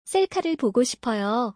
カル ポゴ シポヨ